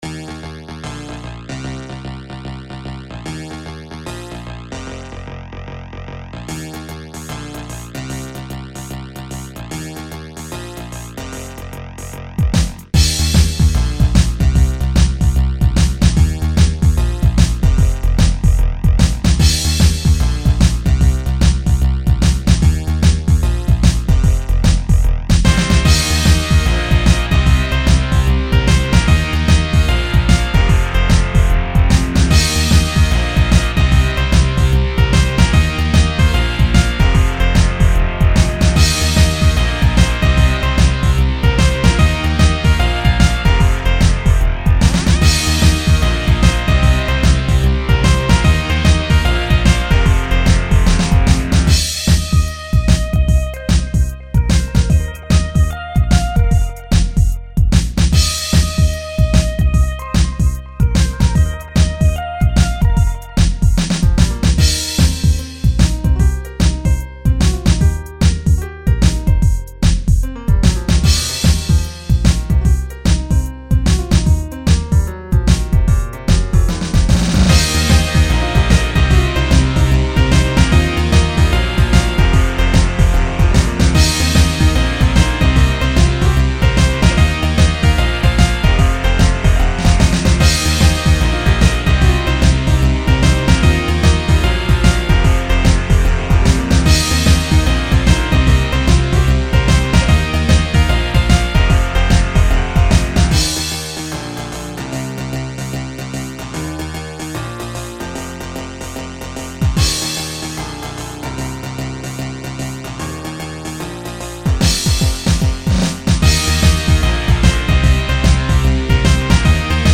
Genre: Funk